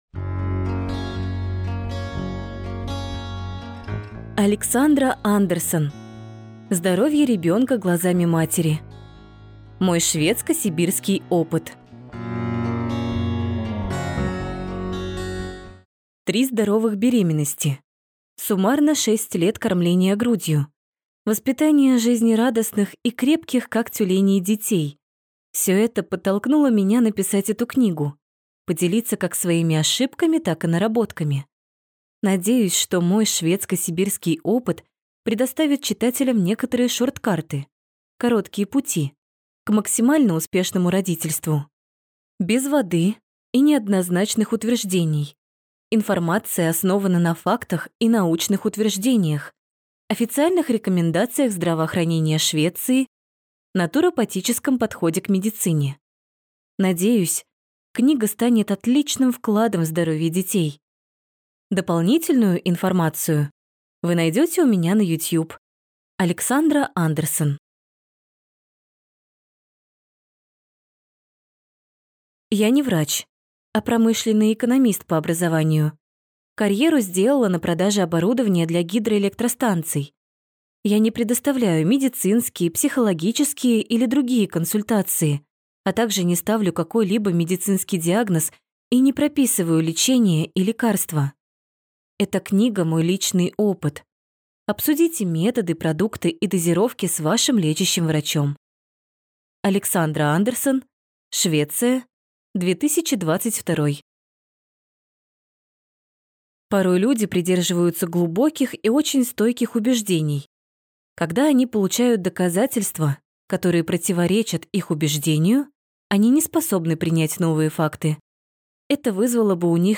Аудиокнига Здоровье ребенка глазами матери. Мой шведско-сибирский опыт | Библиотека аудиокниг